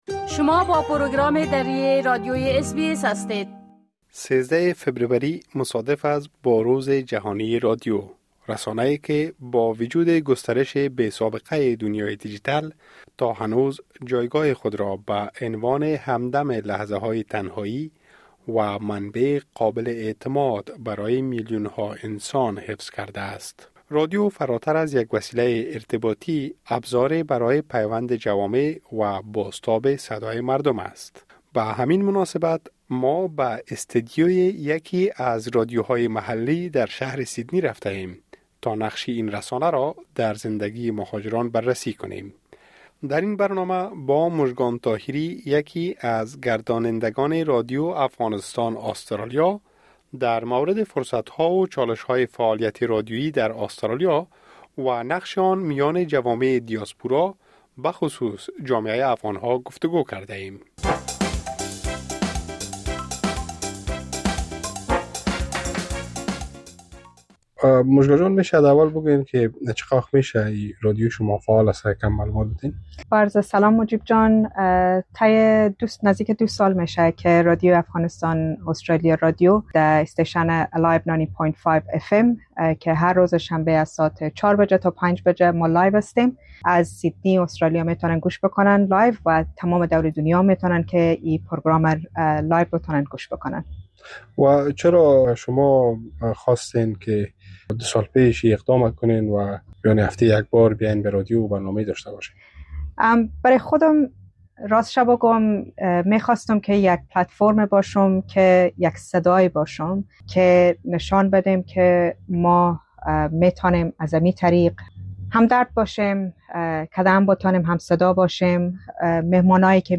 به مناسبت روز جهانی رادیو، ما به استدیوی یکی از رادیوهای محلی در شهر سیدنی رفته‌ایم تا نقش این رسانه را در زندگی مهاجران بررسی کنیم.